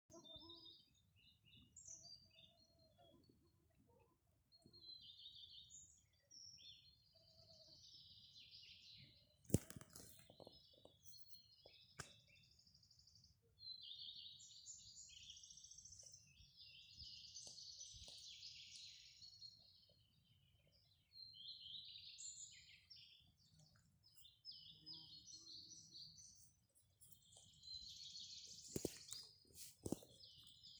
Birds -> Thrushes ->
Common Redstart, Phoenicurus phoenicurus
StatusSinging male in breeding season